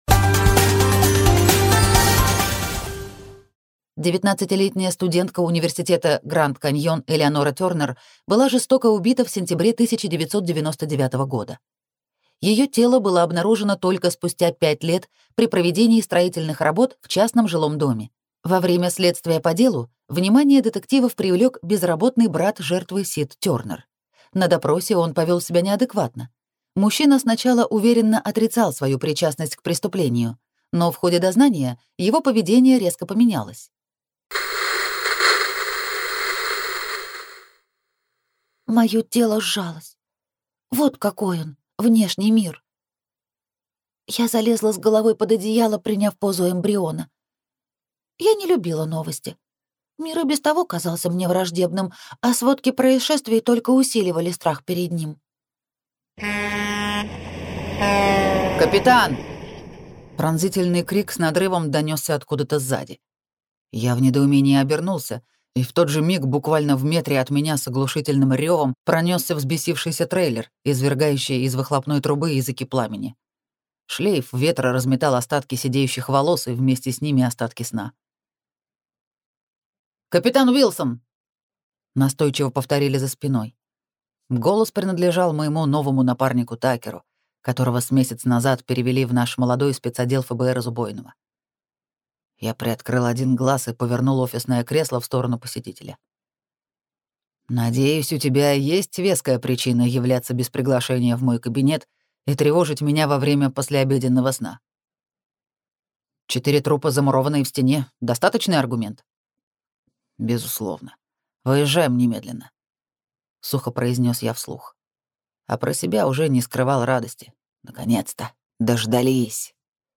Аудиокнига Личность | Библиотека аудиокниг